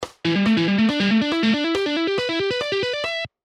Pentatonic-Scale-Guitar-Licks-3.mp3